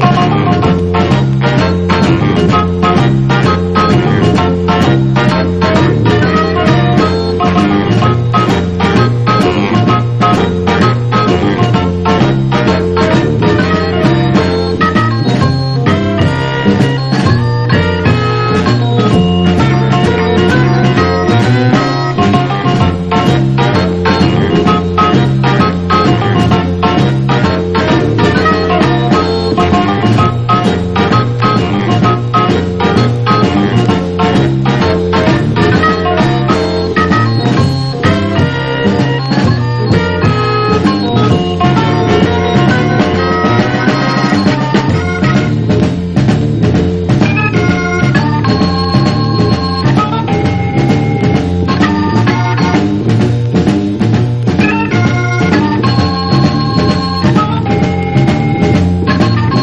EASY LISTENING / EASY LISTENING / CHORUS / SOFT ROCK
女性コーラス入りのヒップな英国イージー・オーケストラ！